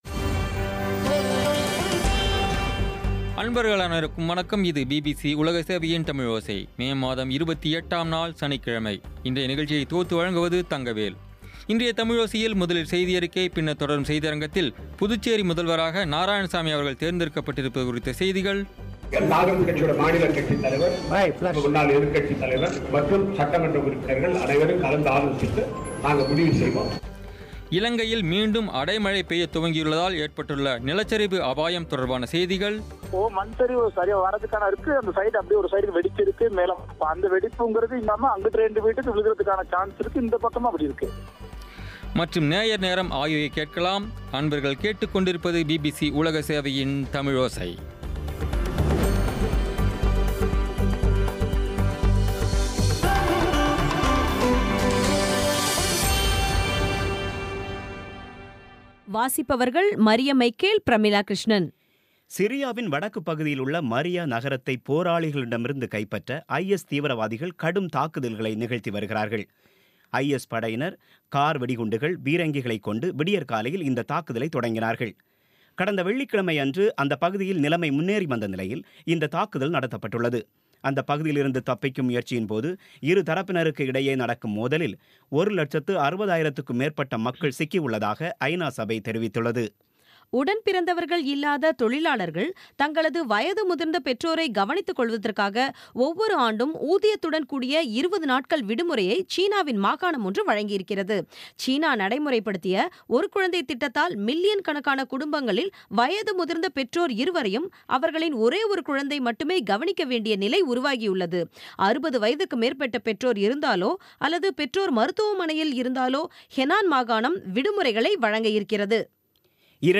இன்றைய தமிழோசையில், முதலில் செய்தியறிக்கை, பின்னர் தொடரும் செய்தியரங்கத்தில்,